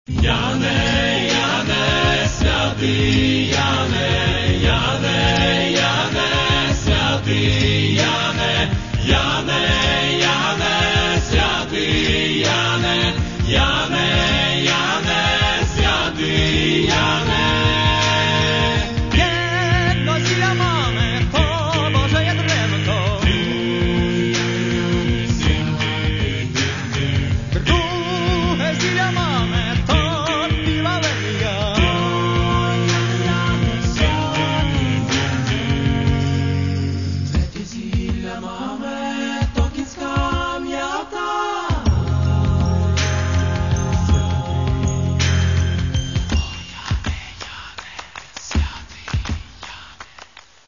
Каталог -> Другое -> Вокальные коллективы
Главное - что их пение завораживает снова и снова.